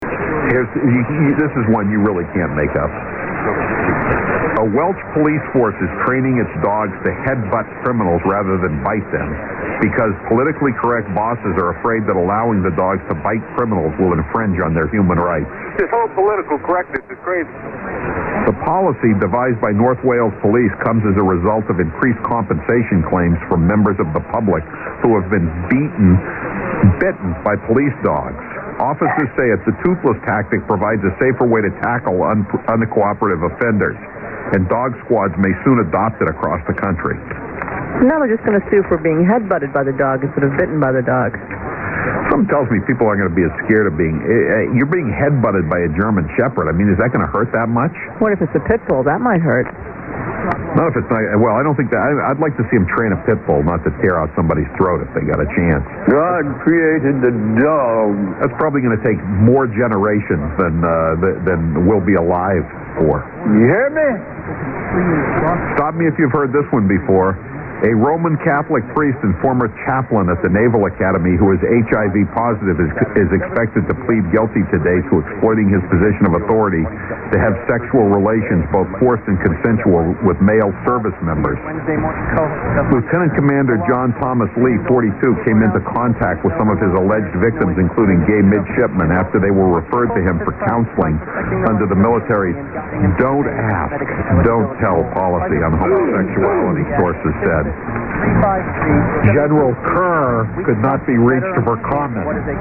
As above but slow AGC AM 12kHz.